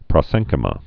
(prŏ-sĕngkĭ-mə)